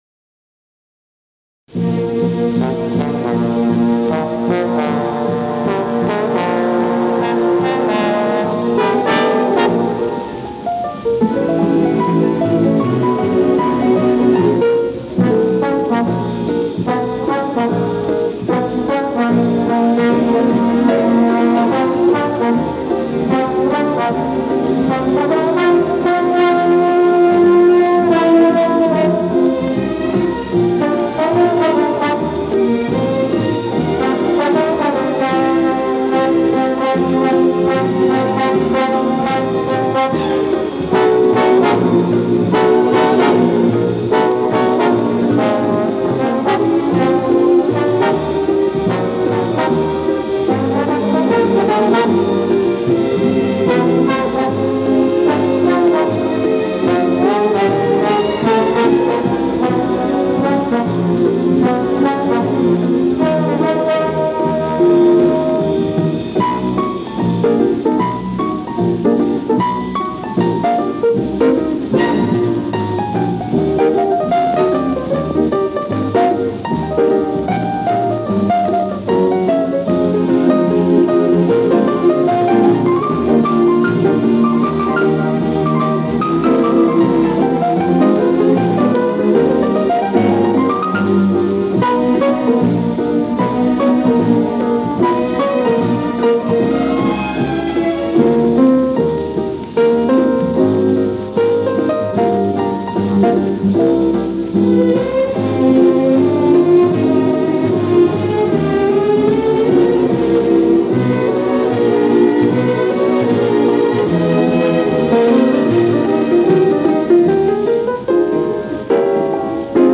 Но всё-таки шипение попытался свести к минимуму: